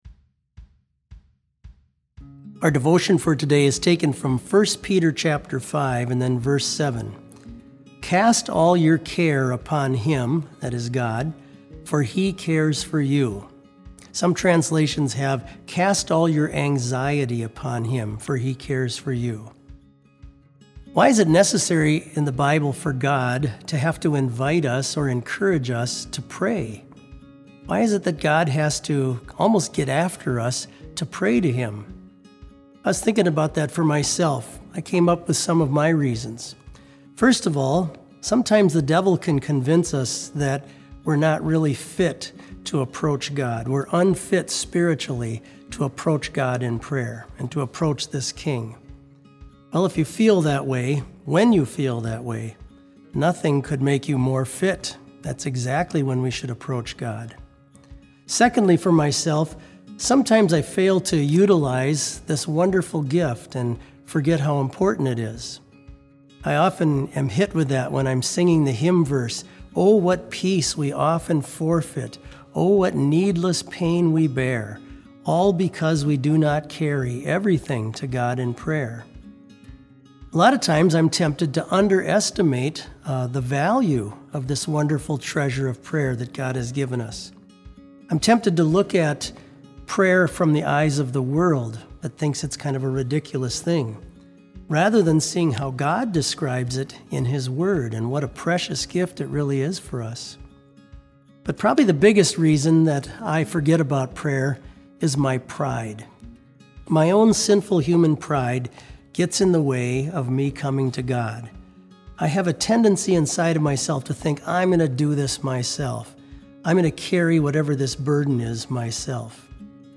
Complete service audio for BLC Devotion - March 30, 2020